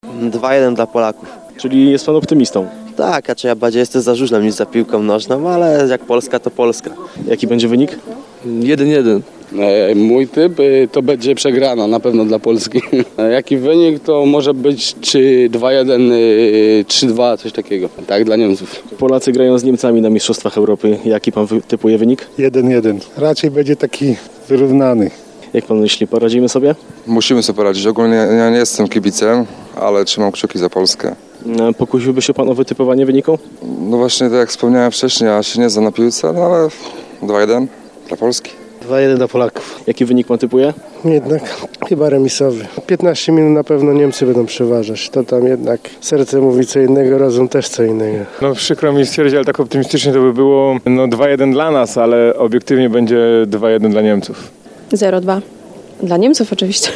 Jakim wynikiem zakończy się ten pojedynek? O typowanie poprosiliśmy mieszkańców naszego regionu.